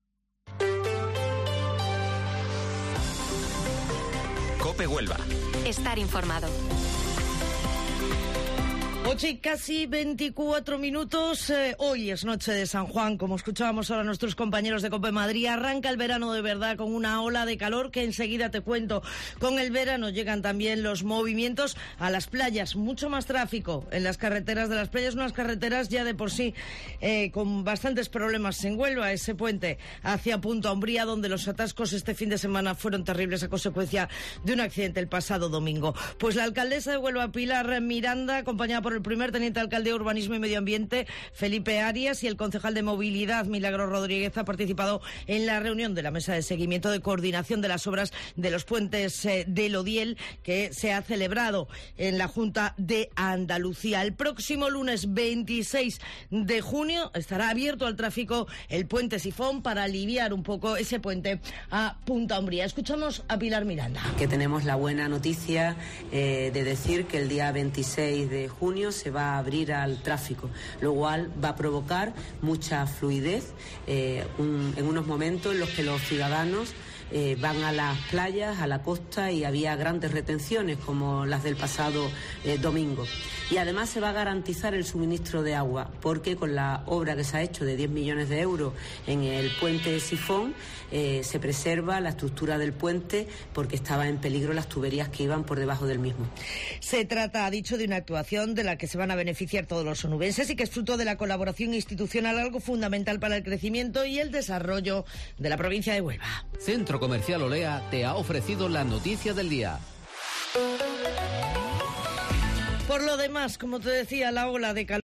Informativo Matinal Herrera en Cope 23 de Junio